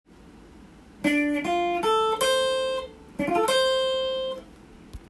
Dm7のコード上で使えるフレーズをtab譜にしてみました。
２～３本ほどの弦をさらっと弾くフレーズになります。